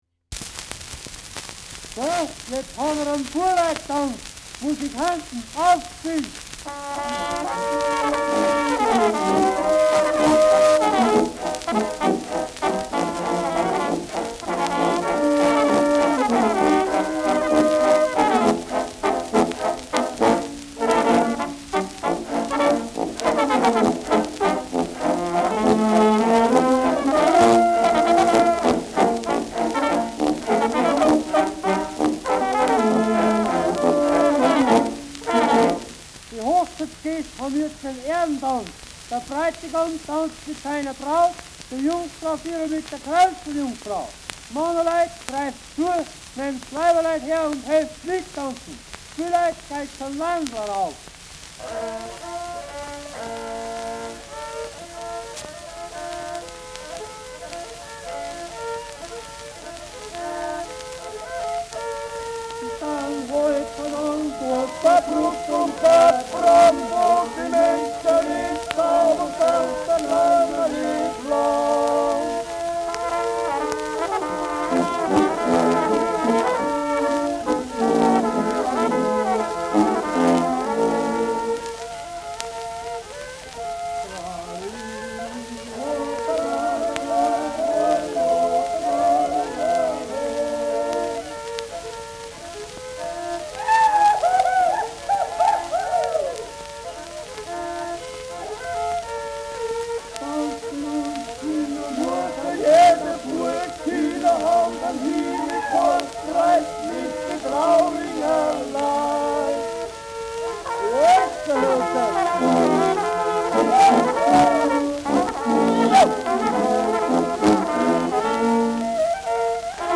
Schellackplatte
präsentes Knistern : abgespielt : leiert : leichtes Rauschen
Andorfer Bauernkapelle (Interpretation)
Szenen aus den Ritualen einer Bauernhochzeit mit Zwischentexten des Prokurators / Hochzeitsladers / Zeremonienmeisters.